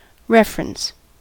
reference: Wikimedia Commons US English Pronunciations
En-us-reference.WAV